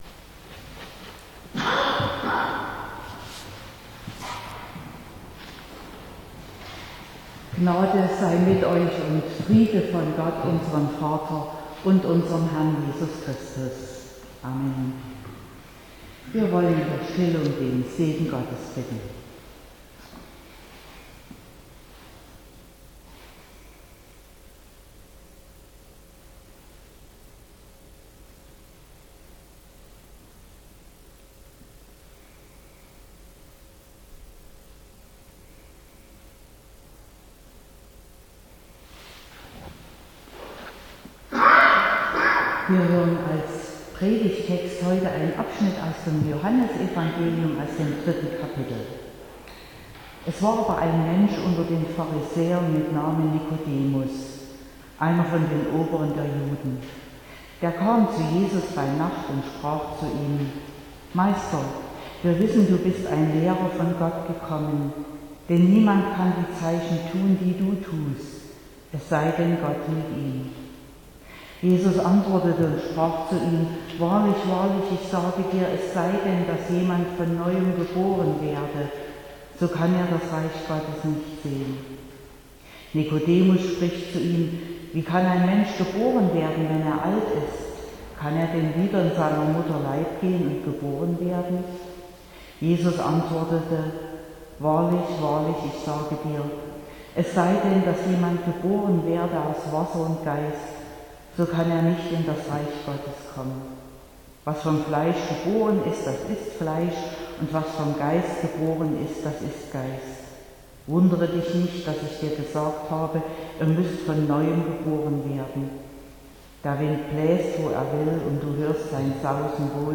30.05.2021 – Gottesdienst
Predigt (Audio): 2021-05-30_Damit_alle_neu_wird_-_geboren_aus_Wasser_und_Geist.mp3 (25,6 MB)